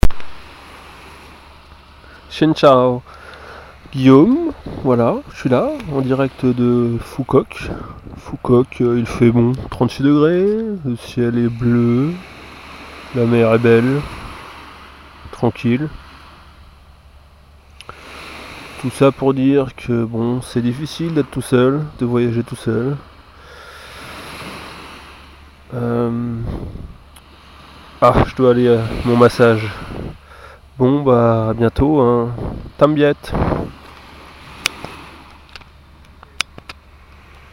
sur la plage a Phu Quoc.MP3